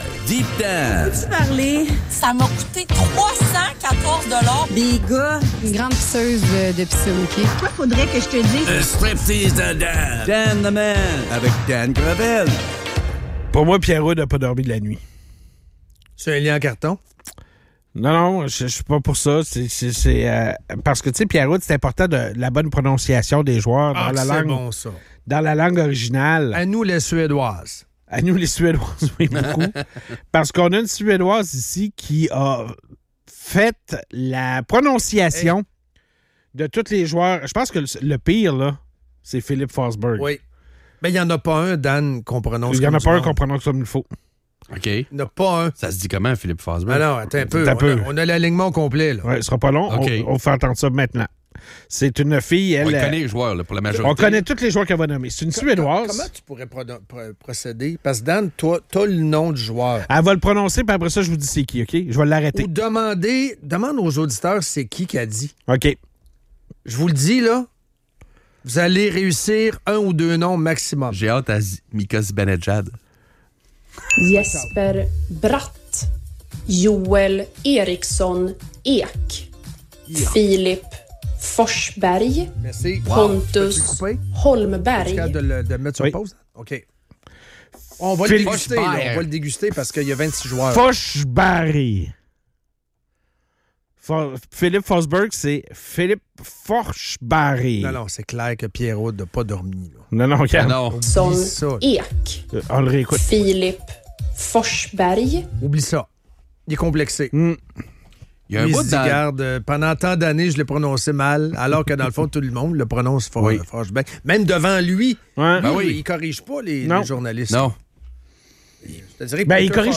La gauche est mise en cause pour sa défense du vol dans les épiceries, avec des discussions animées autour des répercussions de ces actes sur le commerce et la société.